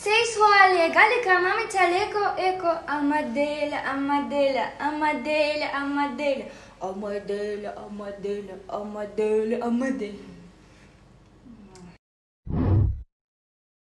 • Качество: 128 kbps, Stereo
Поет девушка (Живой звук)